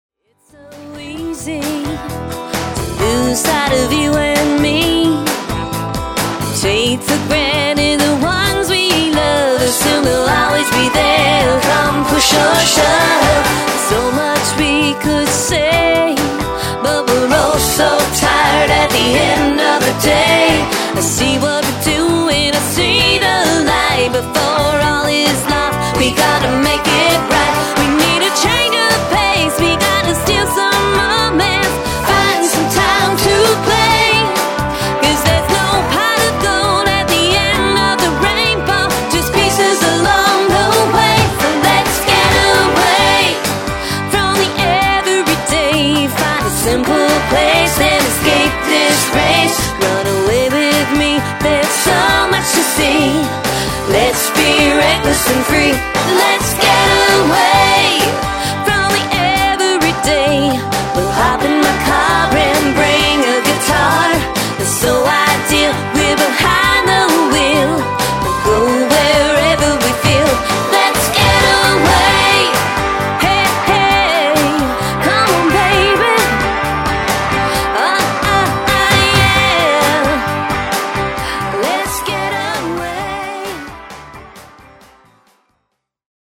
energetic country-pop